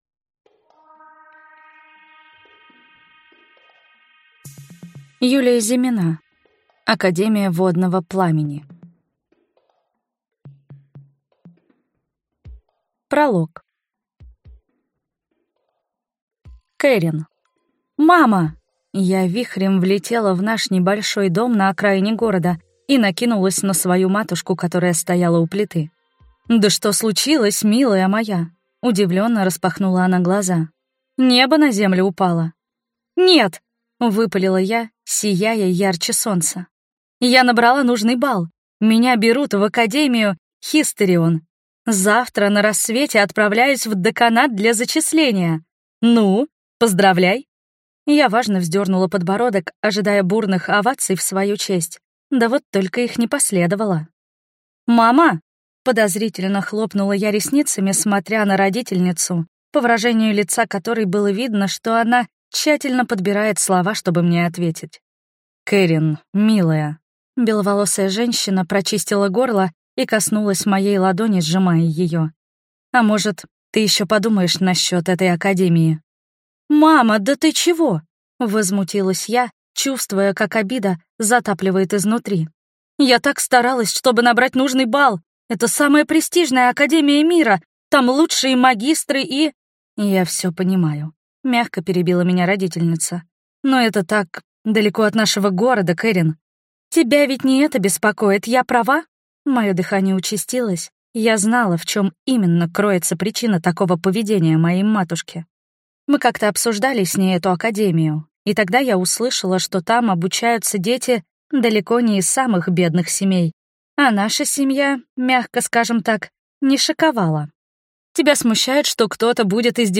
Аудиокнига Академия водного пламени | Библиотека аудиокниг